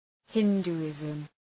Προφορά
{‘hındu:,ızm}